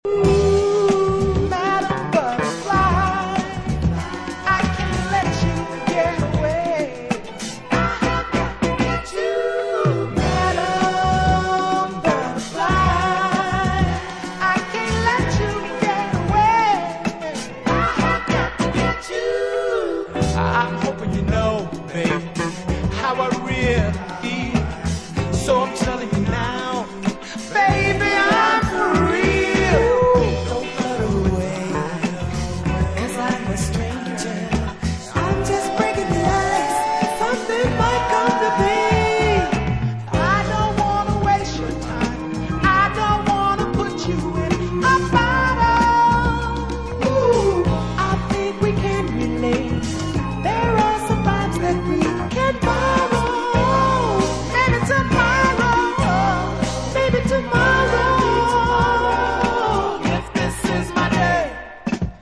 哀愁を帯びつつもソウルフルに盛り上がる傑作！